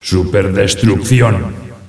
flak_m/sounds/announcer/est/monsterkill.ogg at 9e43bf8b8b72e4d1bdb10b178f911b1f5fce2398
monsterkill.ogg